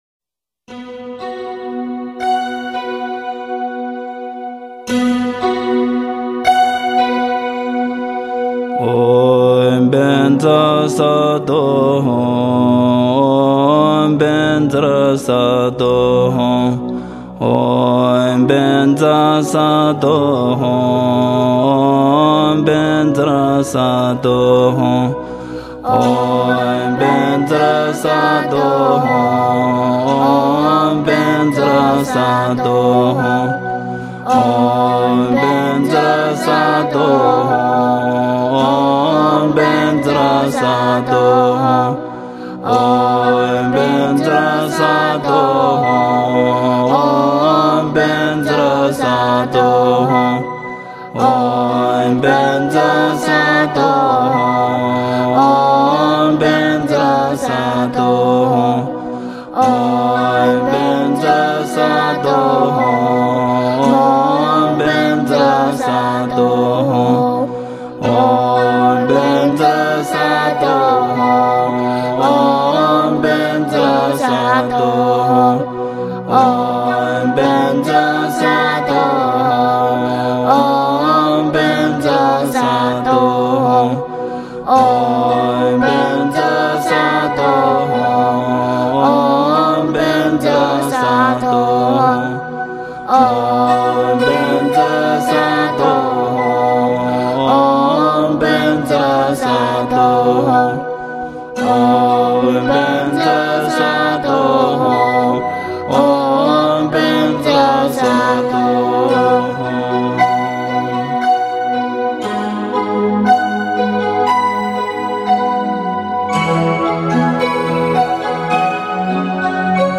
Мантра Ваджрасаттвы (mp3 4,12 Mb. 2:15). Чантинг.